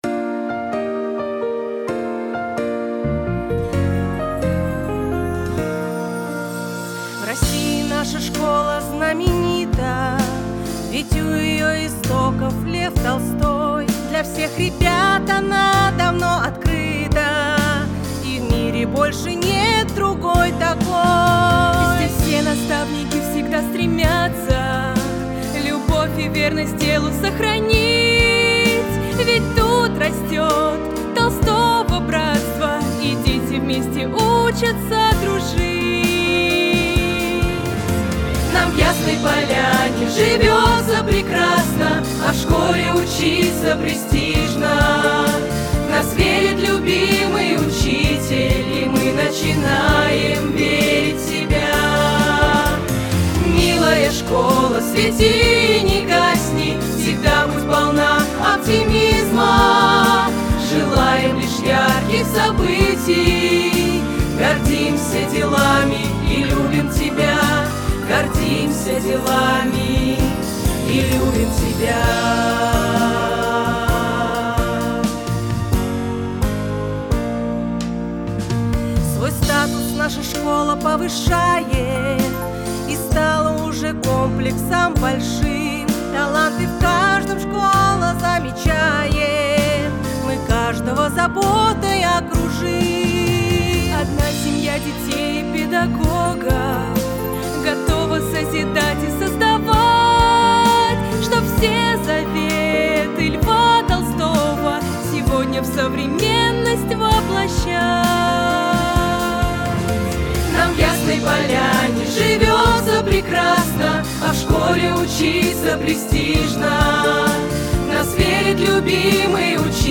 Гимн
Музыка: Александр Ермилов
Слова: педагогический коллектив ГОУ ТО «Яснополянский комплекс»